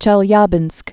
(chĕl-yäbĭnsk, chĭ-lyä-)